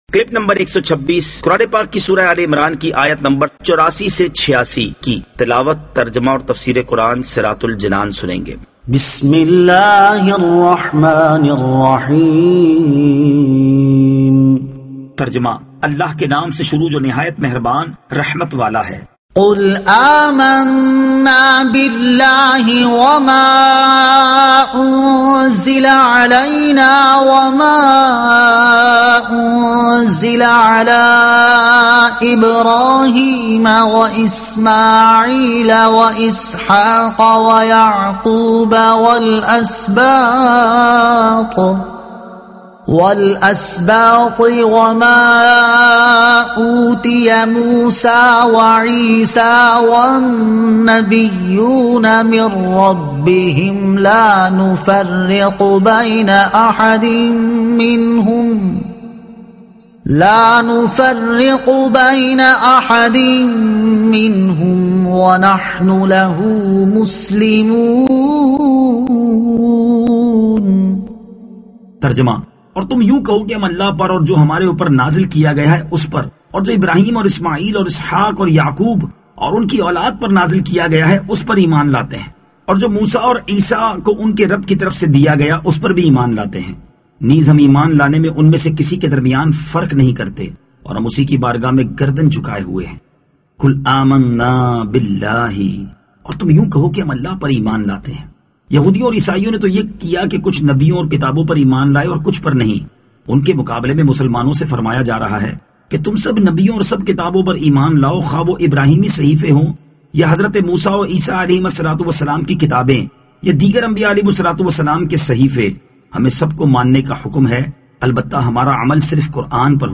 Surah Aal-e-Imran Ayat 84 To 86 Tilawat , Tarjuma , Tafseer